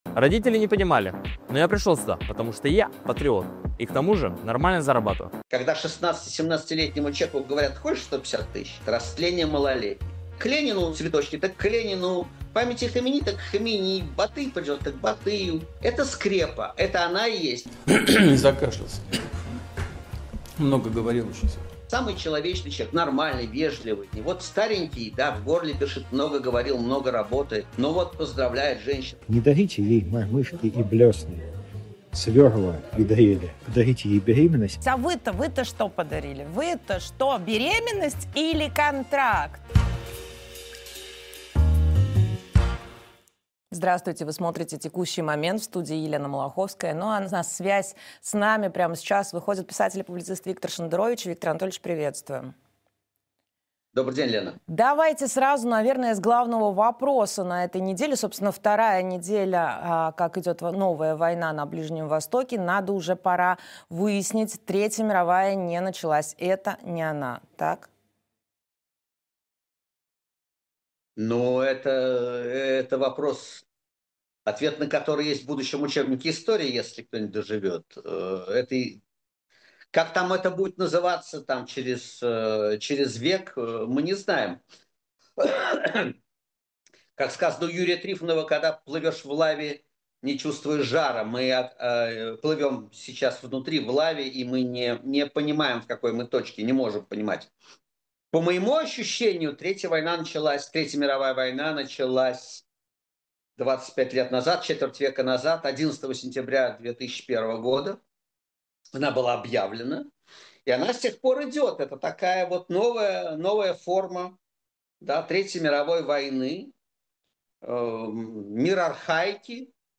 Виктор Шендерович писатель